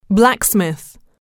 단어번호.0627 대단원 : 3 소단원 : a Chapter : 03a 직업과 사회(Work and Society)-Professions(직업) blacksmith [blǽksmìθ] 명) 대장장이 mp3 파일 다운로드 (플레이어바 오른쪽 아이콘( ) 클릭하세요.) There are few (blacksmiths) today.
blacksmith.mp3